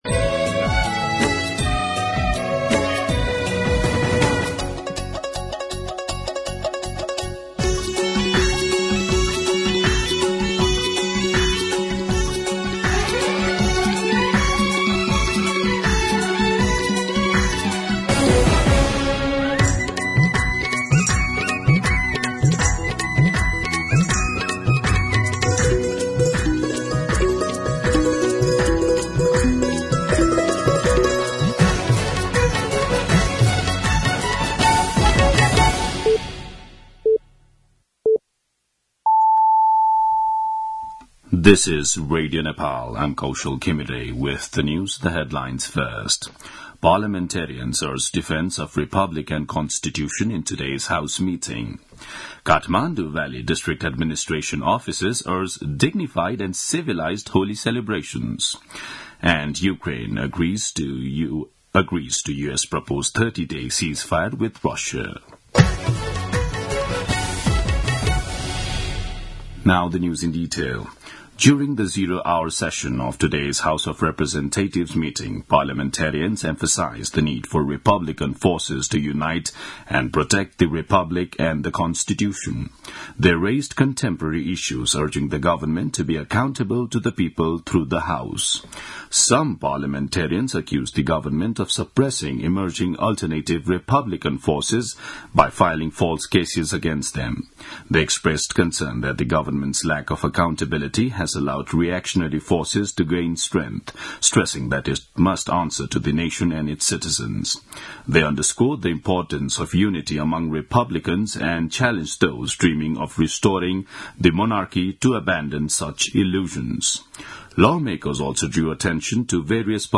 दिउँसो २ बजेको अङ्ग्रेजी समाचार : २९ फागुन , २०८१